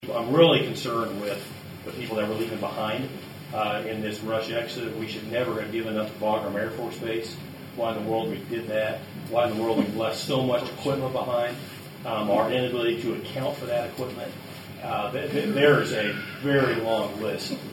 With Congress currently on a summer recess, First District Congressman Tracey Mann visited four Flint Hills communities for town halls Monday.